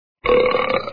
Burp Sound Effect Free Download
Burp